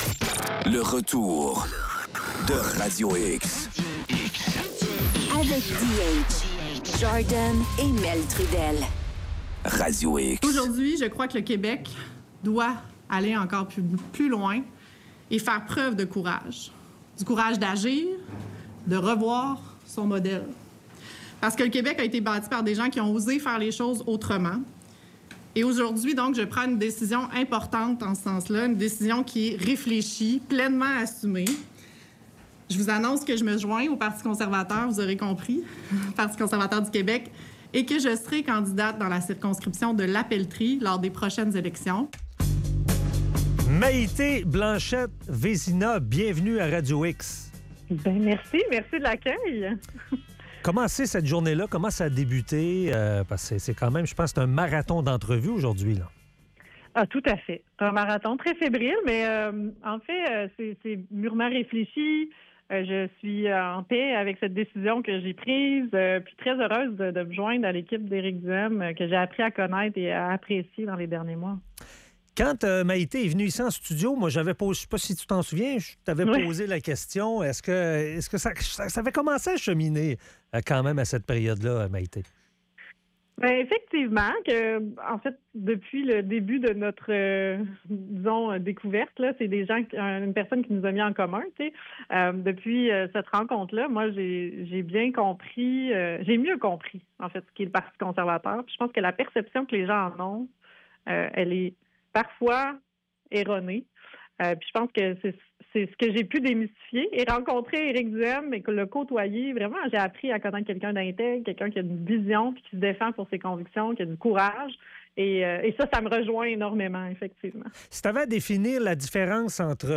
Entrevue avec Maïté Blanchette Vézina, nouvelle députée du Parti Conservateur du Québec.